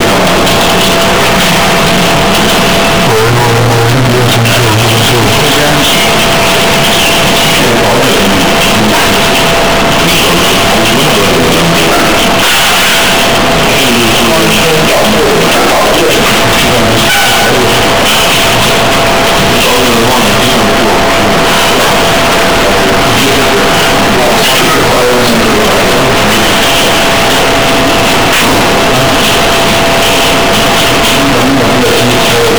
你好，我们录音噪声很大，想通过如下路径配置(PGA--ADC--SW-D3--Effects--SW-D4)，由Effects单元中处理噪声，通过滤波器过滤，请问此方法是否可以改善噪声问题？